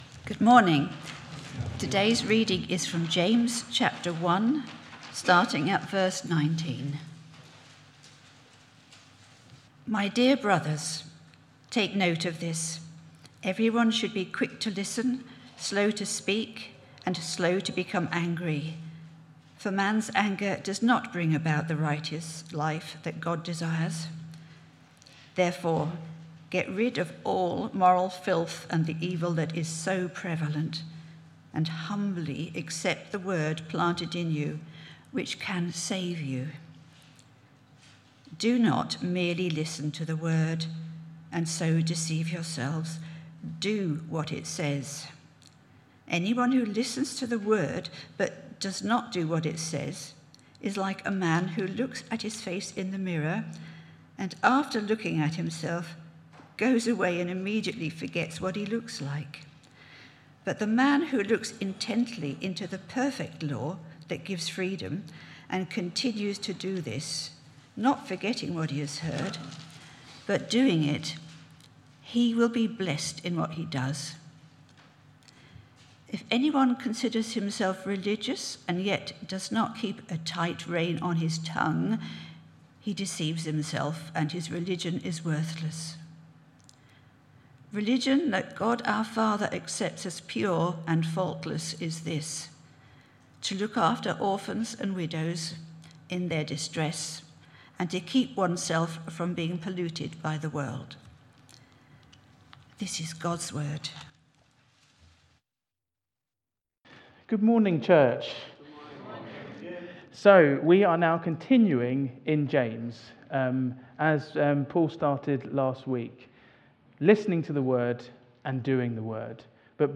Media Library Media for Sunday Service on Sun 22nd Jun 2025 10:00 Speaker
Theme: Living Out the Word Sermon To find a past sermon use the search bar below You can search by date, sermon topic, sermon series (e.g. Book of the Bible series), bible passage or name of preacher (full or partial) .